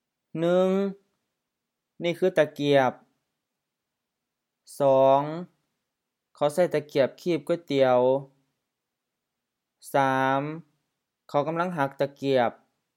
Notes: pronunciation: often with rising tones, especially in isolation